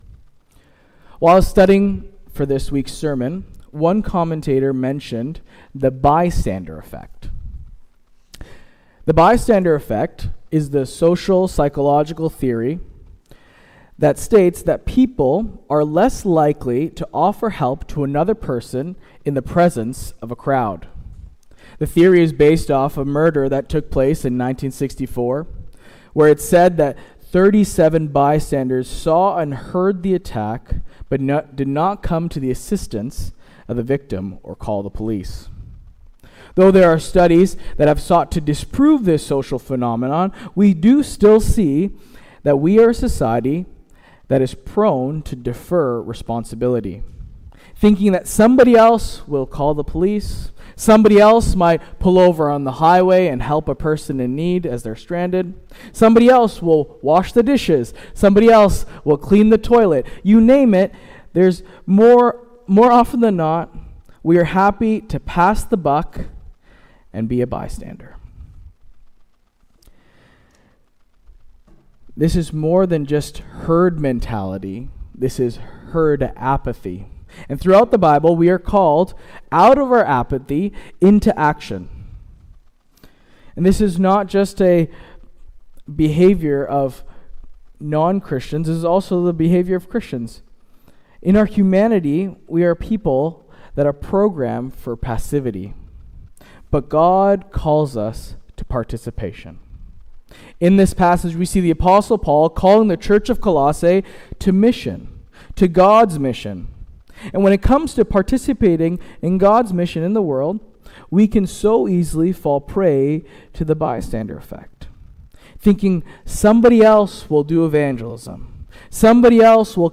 Sermon “No Longer Bystanders”